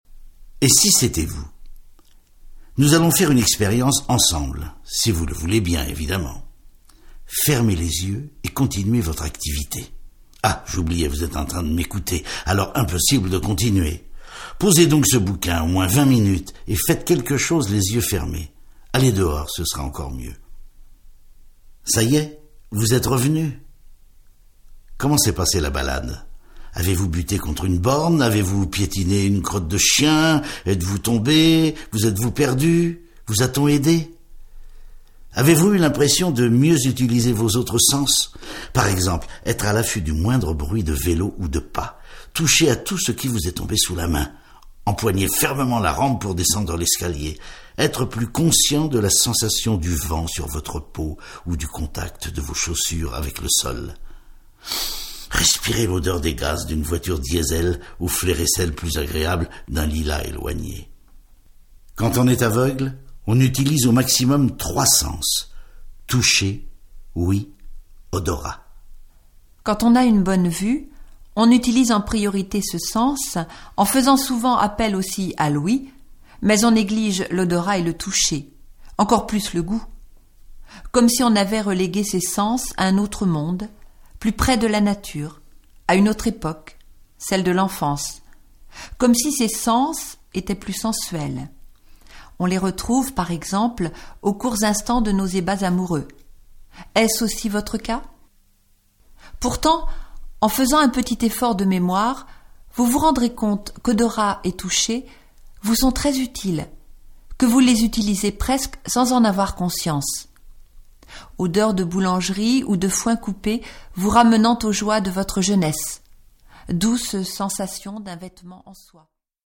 je découvre un extrait - Un malvoyant ouvre les yeux d'une voyante de Monique Bélanger, Yves Belluardo, Martine Childe, Hervé Rutkowski